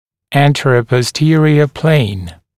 [ˌæntərəpɔs’tɪərɪə pleɪn][ˌэнтэрэпос’тиэриэ плэйн]переднезадняя плоскость, сагиттальная плоскость